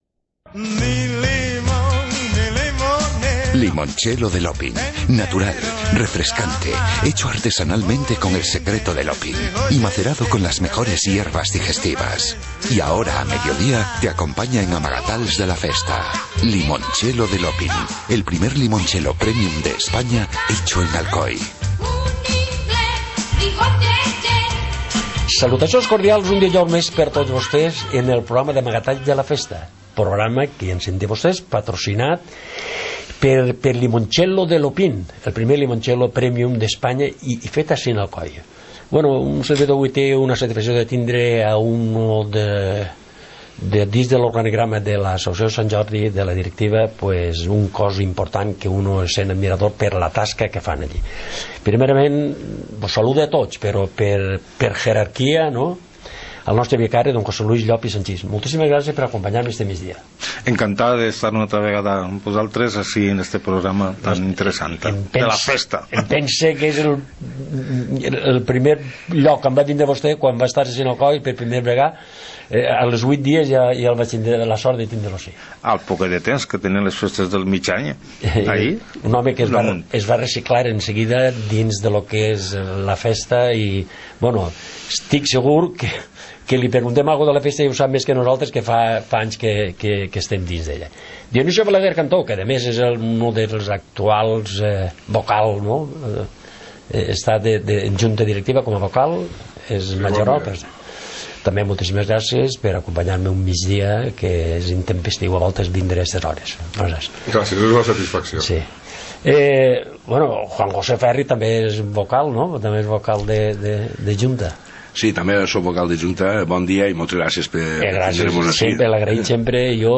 Informativo comarcal - jueves, 01 de febrero de 2018